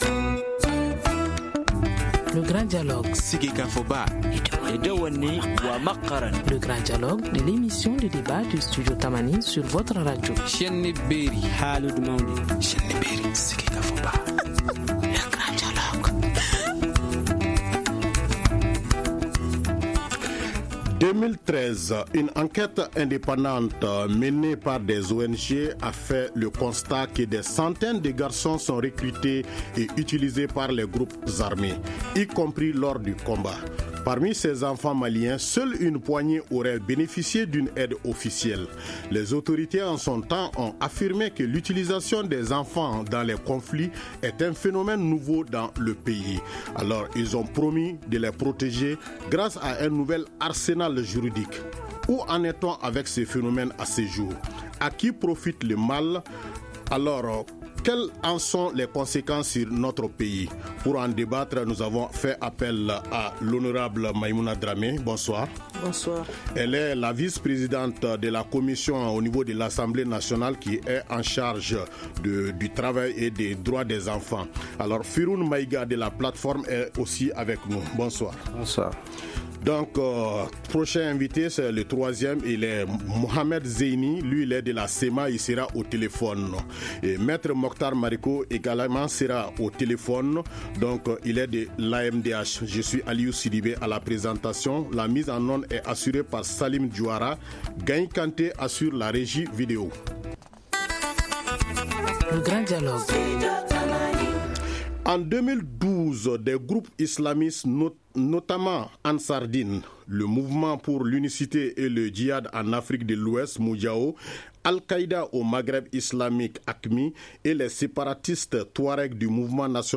Nos invités : Honorable Maimouna DRAME, première vice-présidente de la commission travail et droits des enfants;